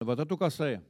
Saint-Jean-de-Monts
Catégorie Locution